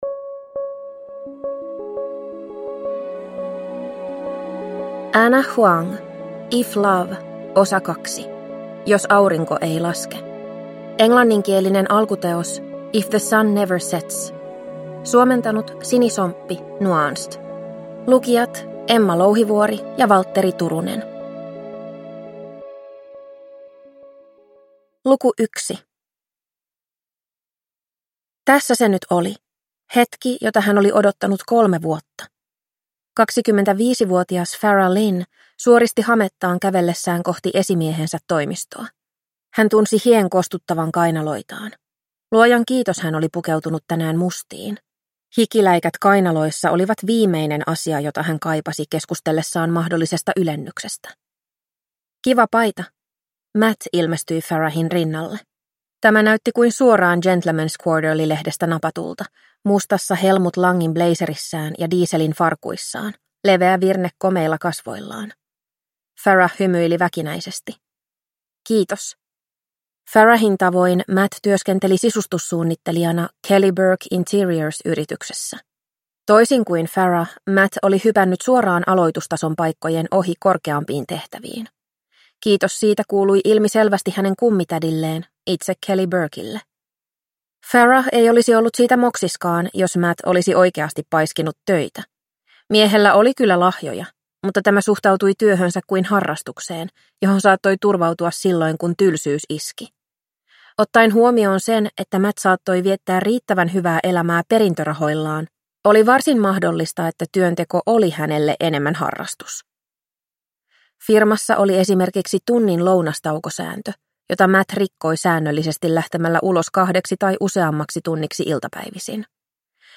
If love 2: Jos aurinko ei laske (ljudbok) av Ana Huang